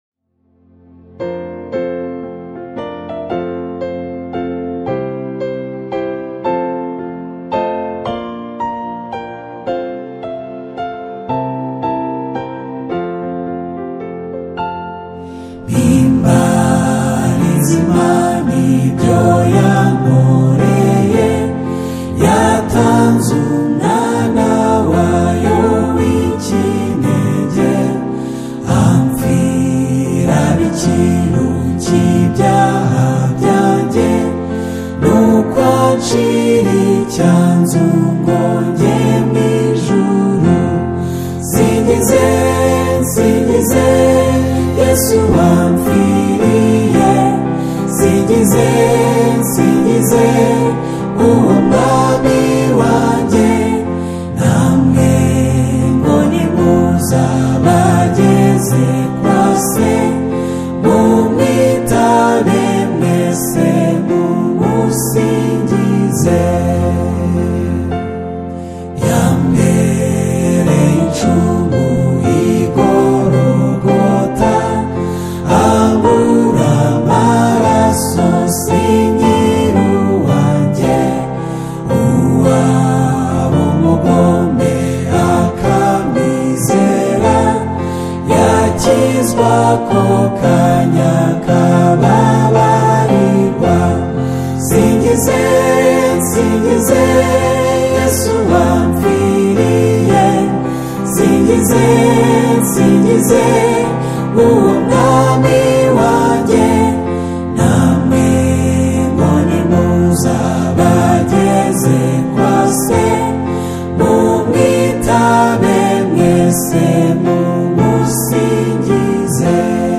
The exquisitely harmonized and atmospheric single
is a "Prism & Pulse" masterpiece of East African worship.